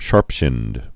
(shärpshĭnd)